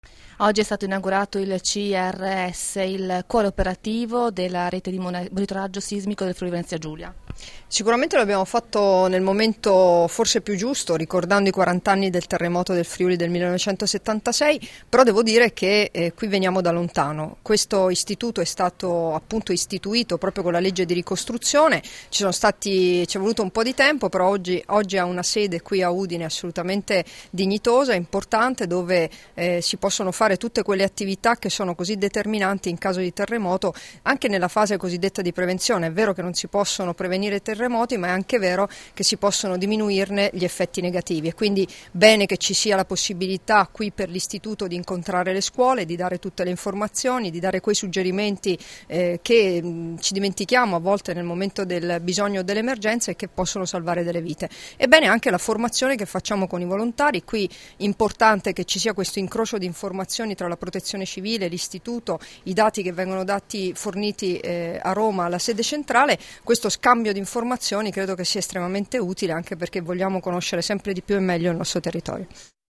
Dichiarazioni di Debora Serracchiani (Formato MP3) [1164KB]
a margine dell'inaugurazione della nuova sede del Centro di Ricerche Sismologiche (CRS) dell'Istituto nazionale di Oceanografia e Geofisica Sperimentale (OGS), rilasciate a Udine il 19 aprile 2016